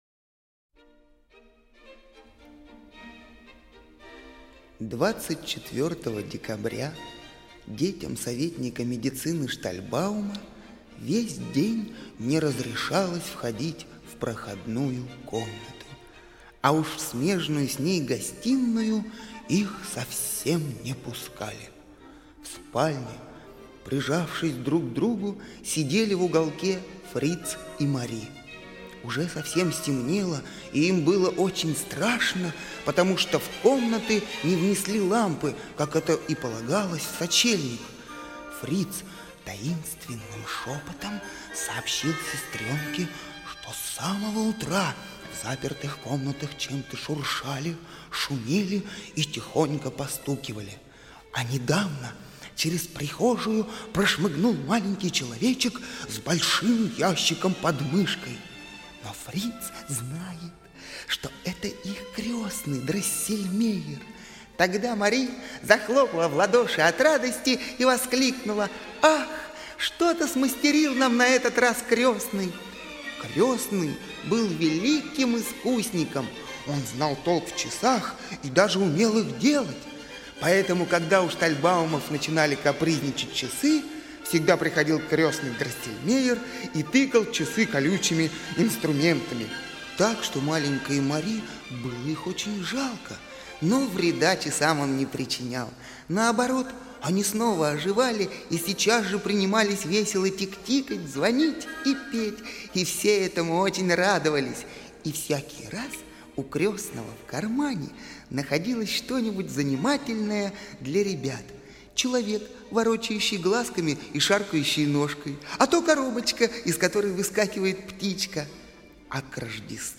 Щелкунчик - музыкальная сказка Чайковского - слушать